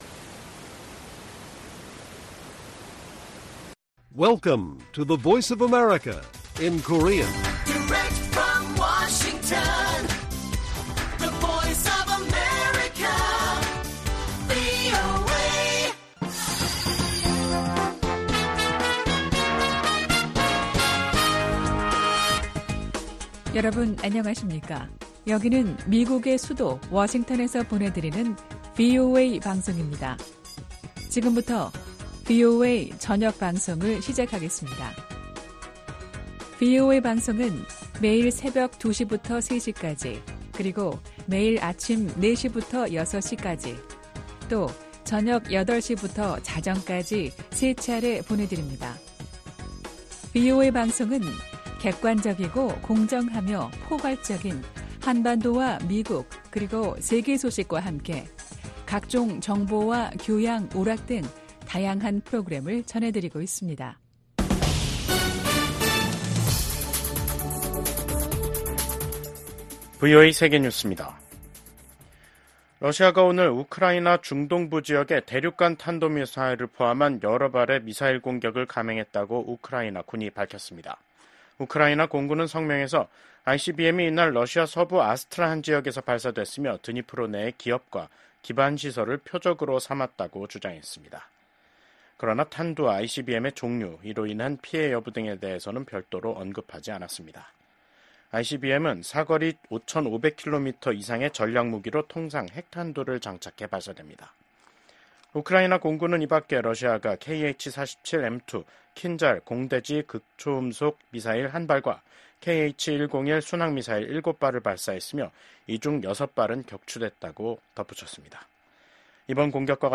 VOA 한국어 간판 뉴스 프로그램 '뉴스 투데이', 2024년 11월 21일 1부 방송입니다. 미국 국무부는 우크라이나 전선에 배치된 북한군이 합법적인 군사 표적임을 재확인했습니다. 약 2년 전 만료된 북한인권법 연장을 승인하는 법안이 미국 하원 본회의를 통과했습니다. 유엔총회 제3위원회가 20년 연속 북한의 심각한 인권 상황을 규탄하는 결의안을 채택했습니다.